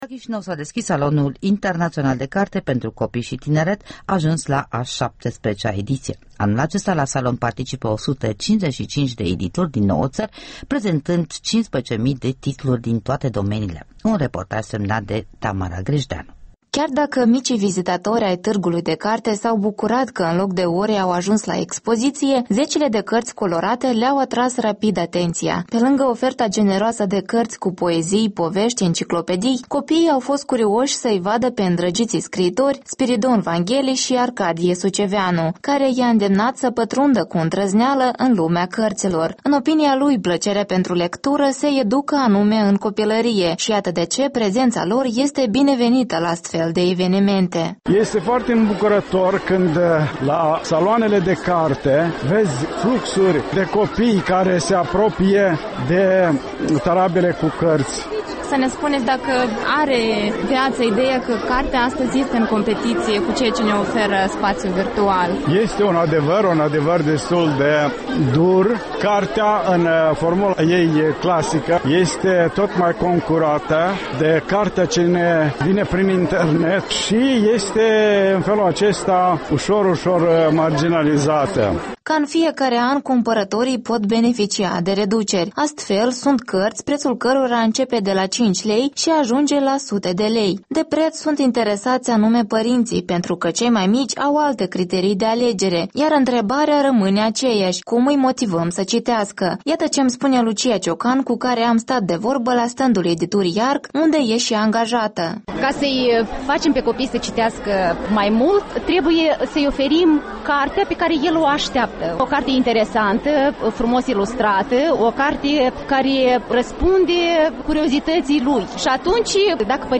Un reportaj de la Tîrgul de carte pentru copii și tineret